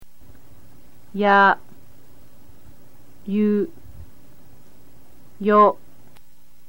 In this lesson you will learn the Hiragana form of the sounds YA, YU, YO, WA, (W)O, N. To see the stroke order, all you need to do is hover your mouse over the graphic of the character shown on the page. To hear how to pronounce the characters, click your mouse on any picture of the character group.